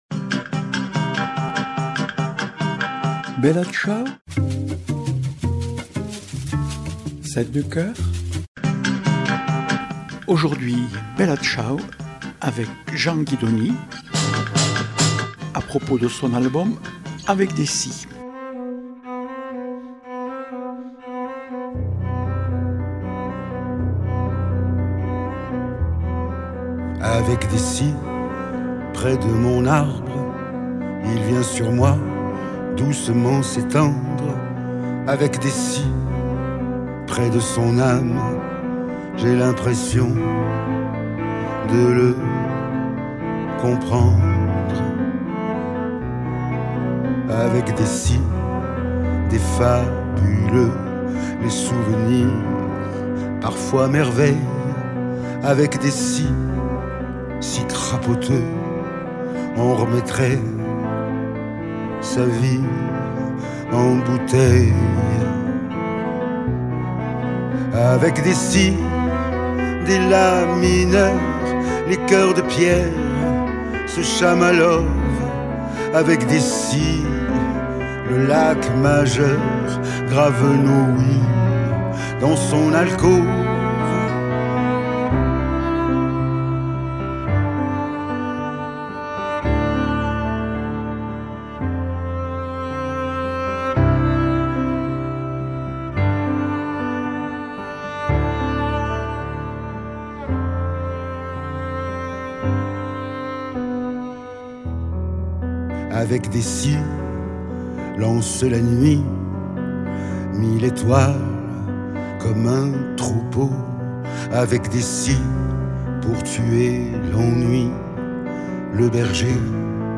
Invité(s) : Jean Guidoni, chanteur et parolier.